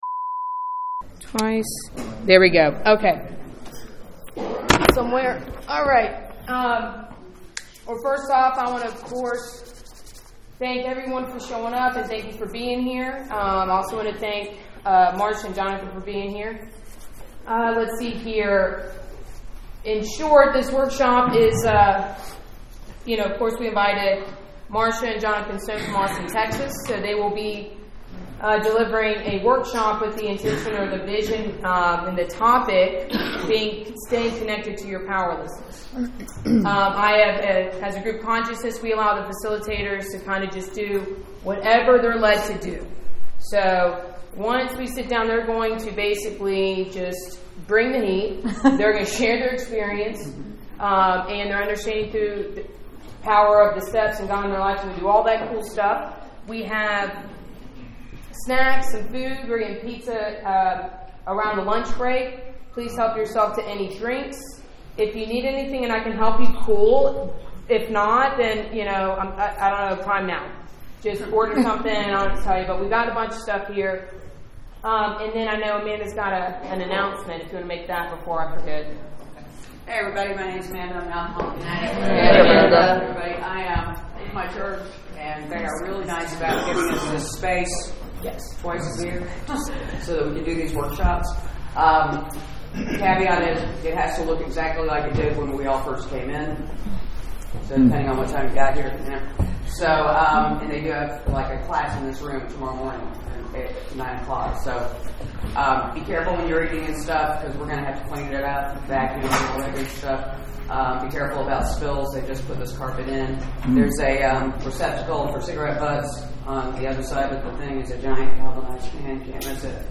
Steps 1-3 Workshop | Atlanta, Ga